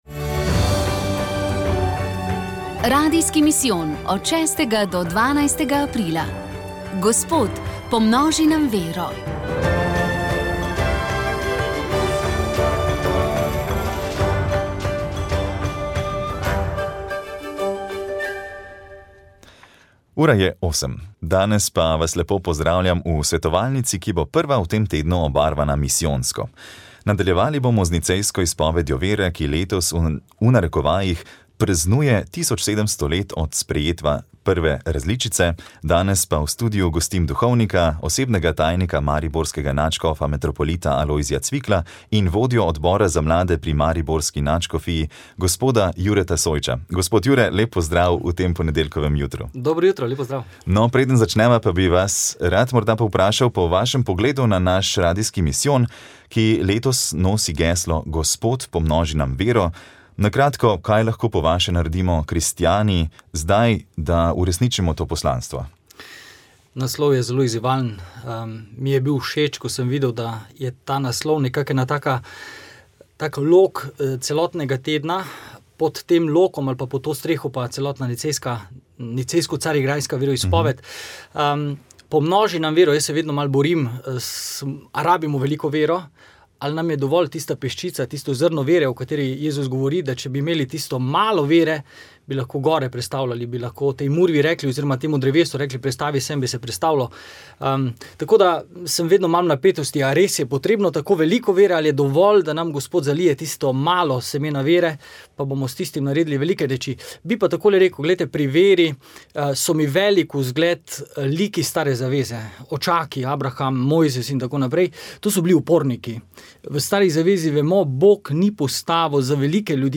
2. dan: Misijonski nagovor - mariborski nadškof Alojzij Cvikl